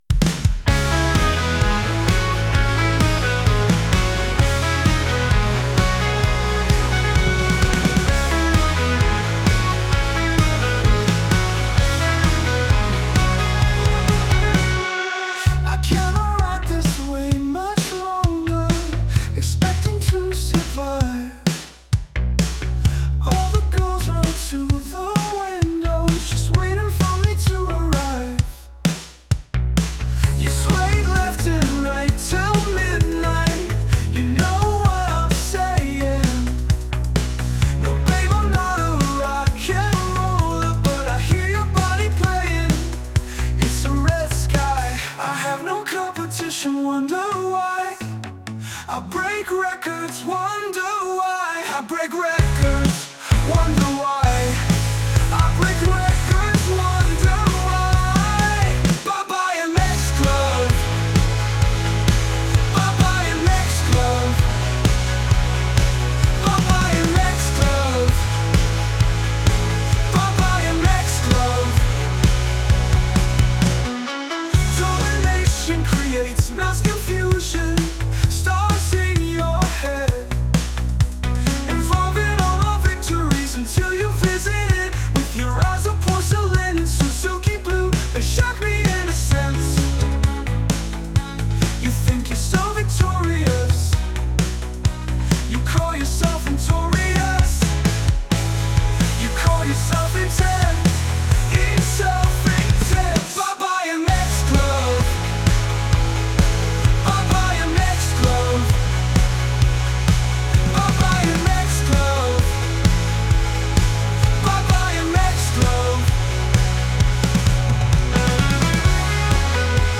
indie:
ByeByeMXGloveIndie.mp3